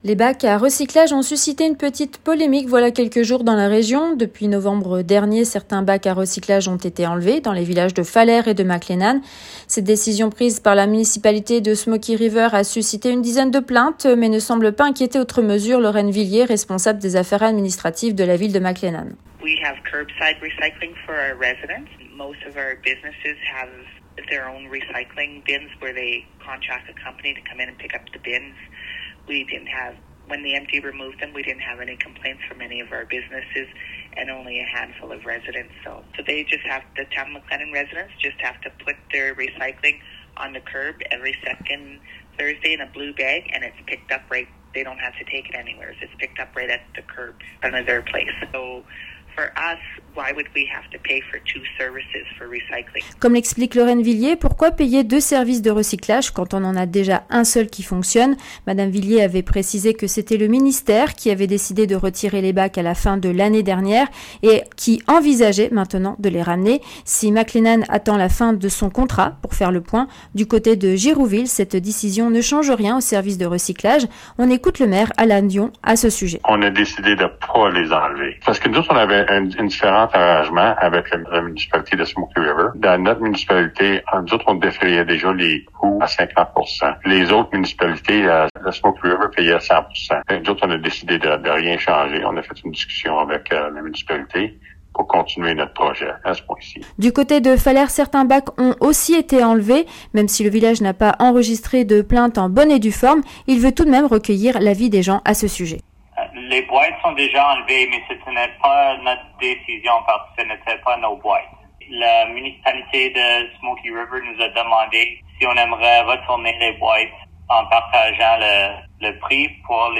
s'est entretenue avec les principaux représentants de Girouxville, Falher et McLennan.